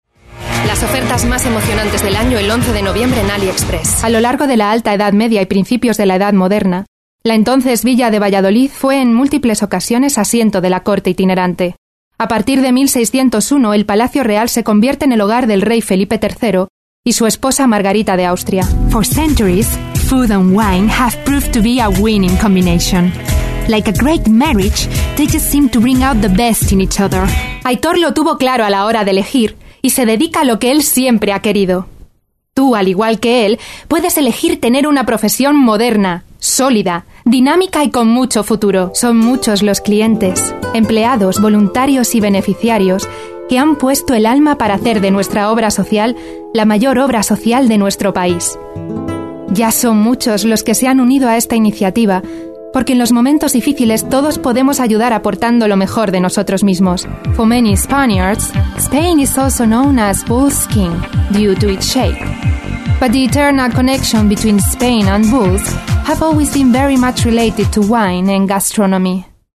Female / 30s / Spanish
Showreel